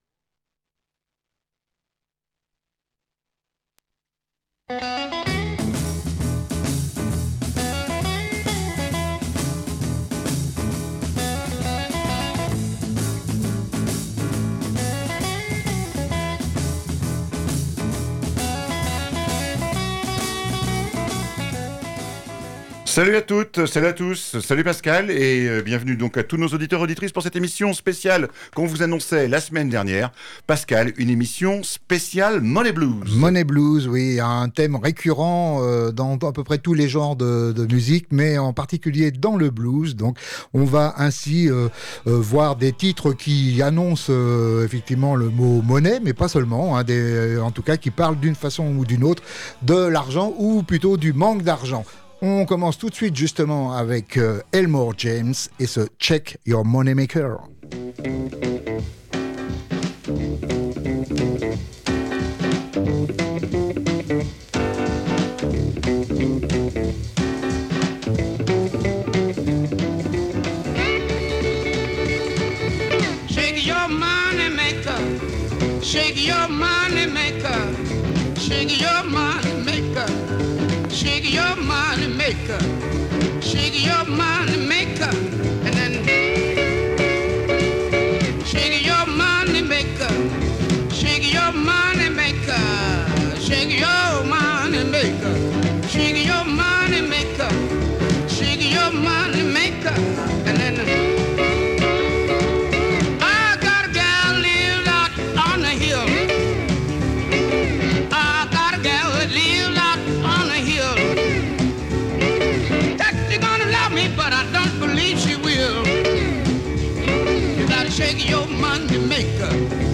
Blues Club vous propose d’illustrer à travers quelques grand titres ce thème de la monnaie avec cette émission spéciale « Money Blues » !